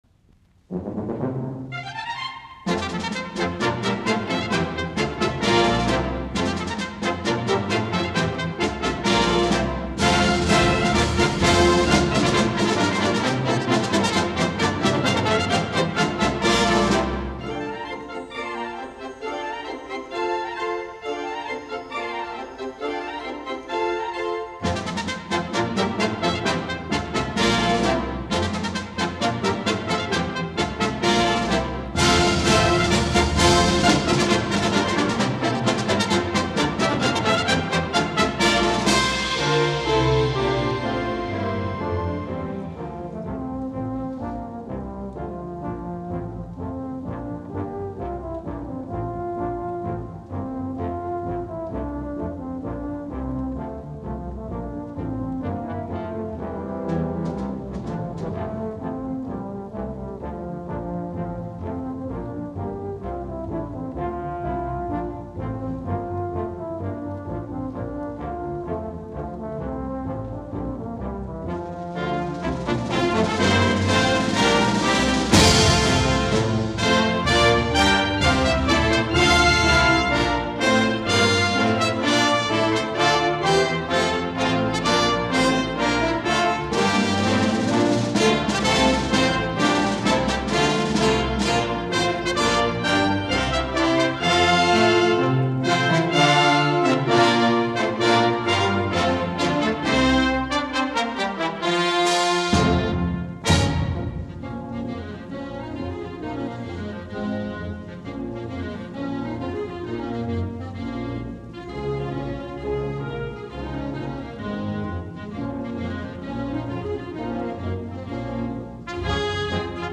Soitinnus: Puhallinorkesteri.